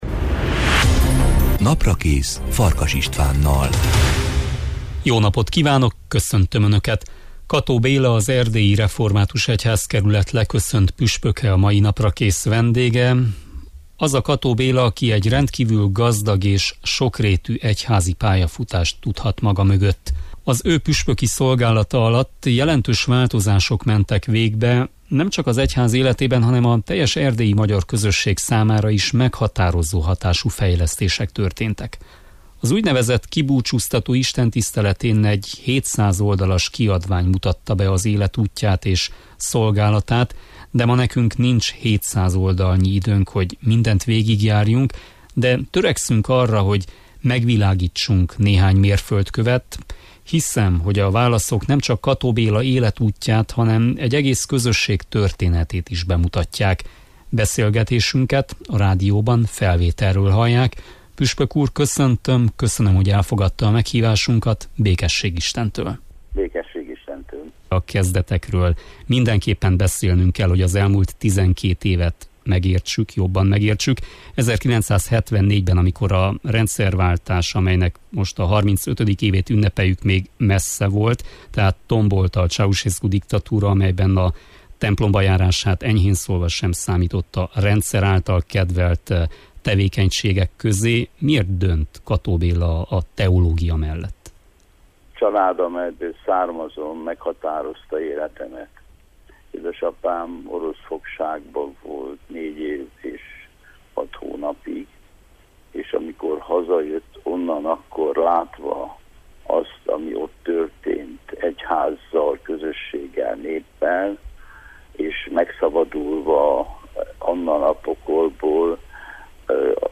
Kató Béla, az Erdélyi Református Egyházkerület leköszönt püspöke a vendégem, aki egy rendkívül gazdag és sokrétű egyházi pályafutást tudhat maga mögött. Az ő püspöki szolgálata alatt jelentős változások mentek végbe, nemcsak az egyház életében, hanem a teljes erdélyi magyar közösség számára is meghatározó hatású fejlesztések történtek.